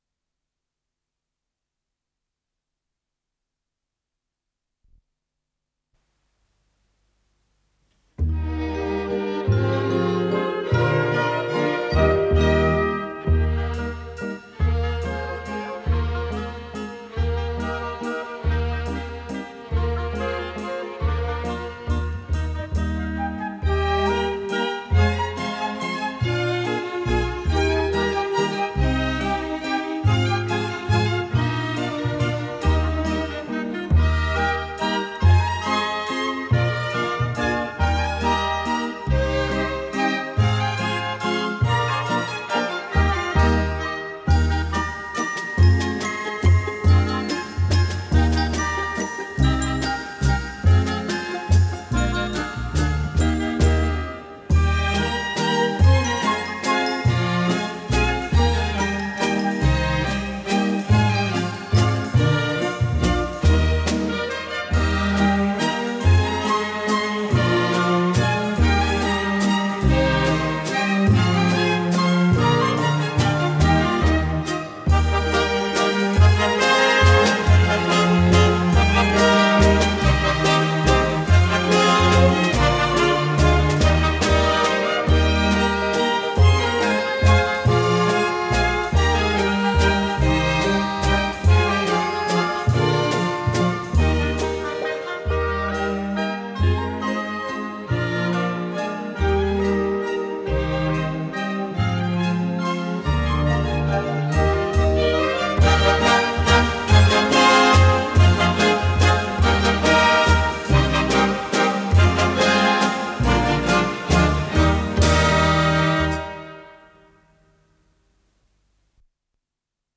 ПодзаголовокЗаставка, ми бемоль мажор
КомпозиторыЭстонская народная
ВариантДубль моно